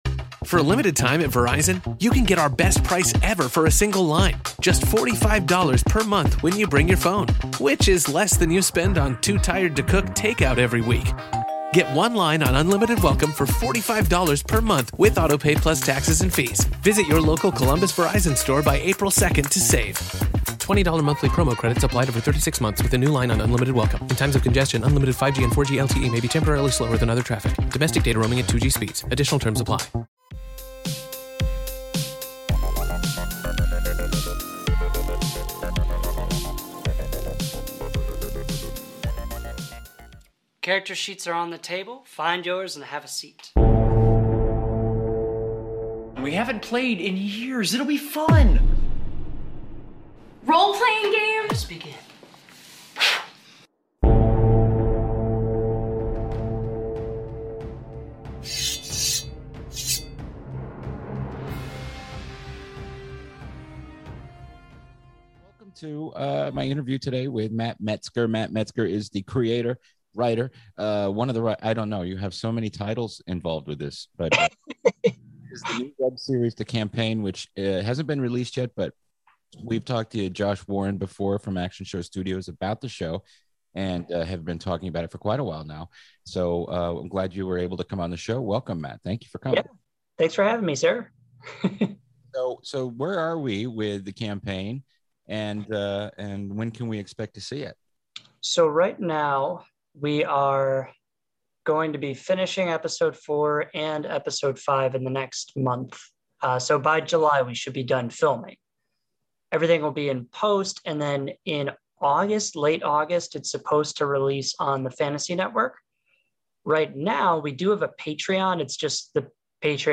Radio Labyrinth Presents - Interviews